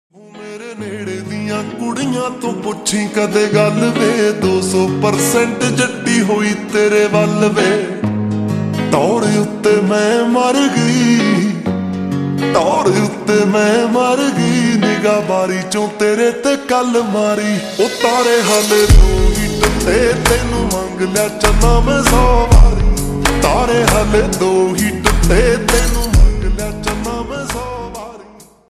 Punjabi sang
(Slowed + Reverb)